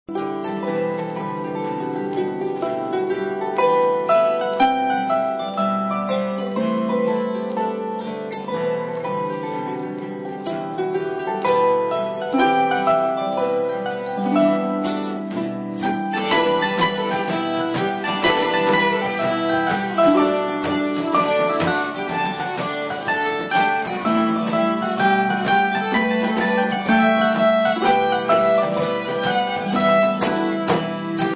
Harp
Fiddle
Guitar
Bodhran